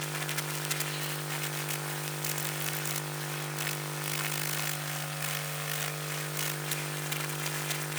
pgs/Assets/Audio/Sci-Fi Sounds/Hum and Ambience/Hum Loop 10.wav at 7452e70b8c5ad2f7daae623e1a952eb18c9caab4
Hum Loop 10.wav